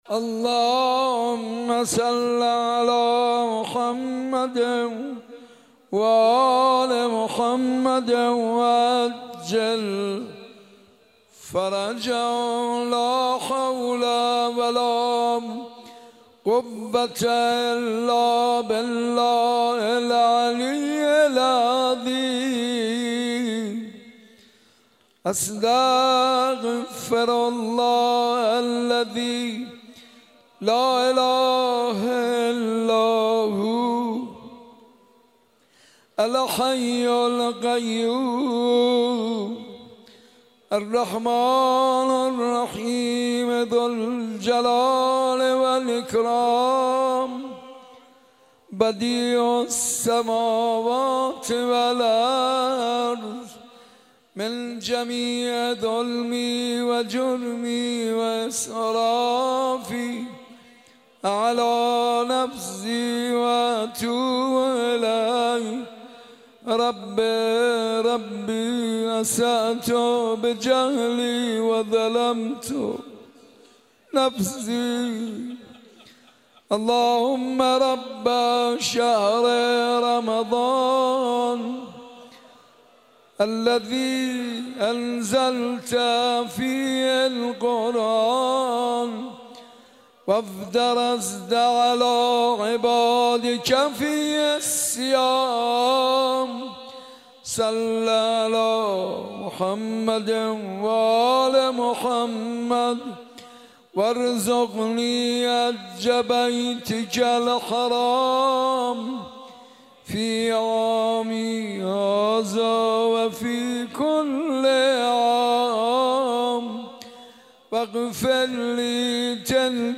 شب سوم رمضان المبارک 1394
باز هم توبه کنان پشت درت آمده ام | مناجات